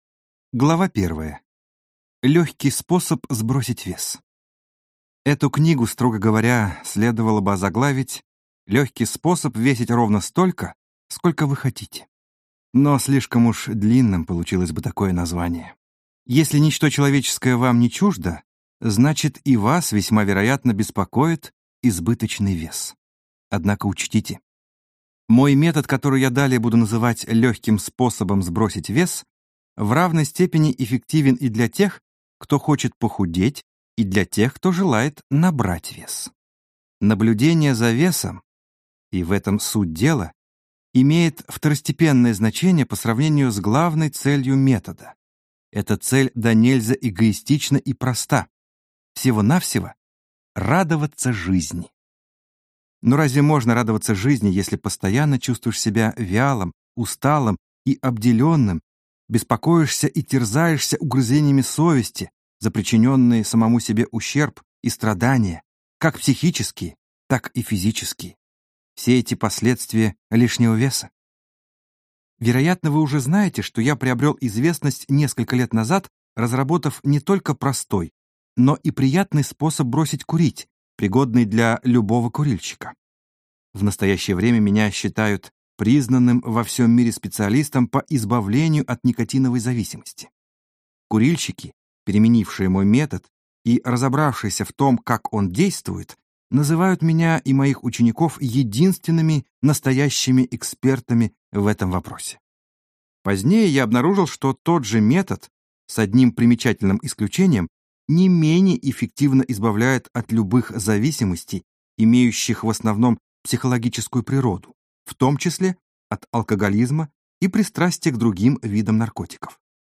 Аудиокнига Легкий способ сбросить вес - купить, скачать и слушать онлайн | КнигоПоиск